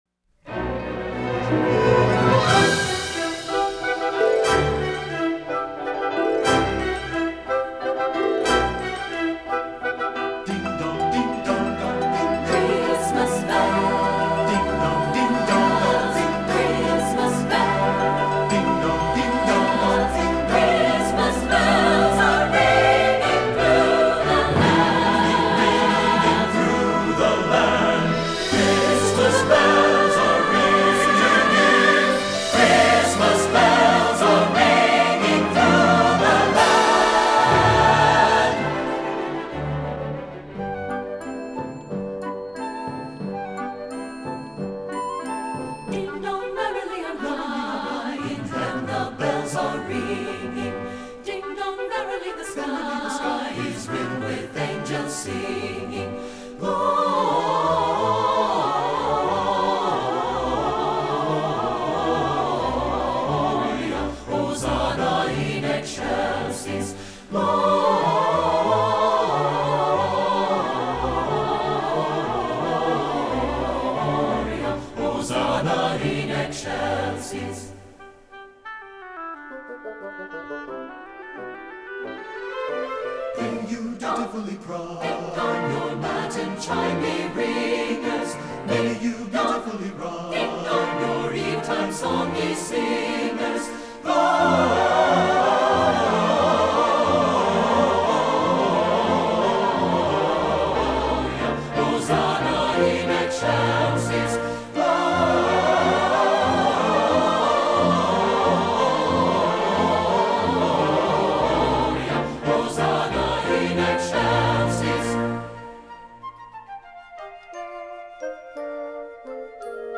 • Soprano
• Alto
• Tenor
• Bass
• Piano
Studio Recording
Ensemble: Mixed Chorus
Key: E minor
Accompanied: Accompanied Chorus